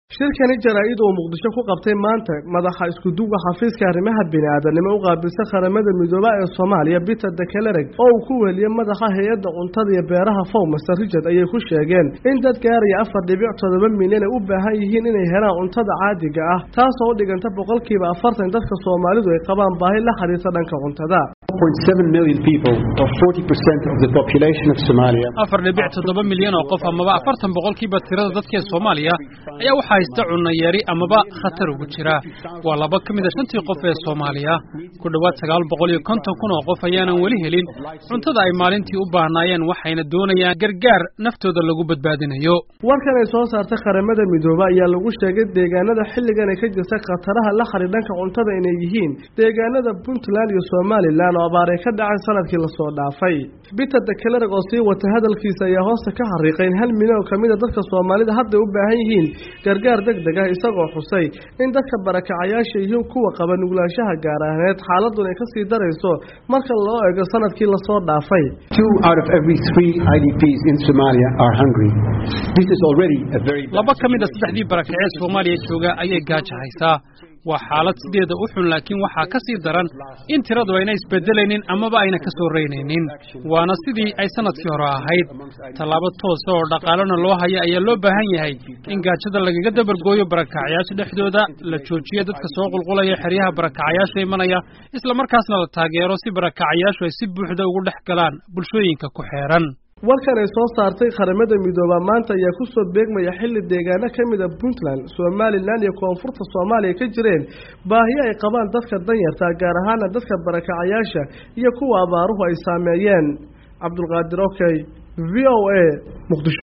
Peter de Clercq oo ah madaxa xafiiska isku duwidda gargaarka ee QM, ayaa arrintan ka sheegay shir jaraa'id oo uu ku qabtay Muqdisho.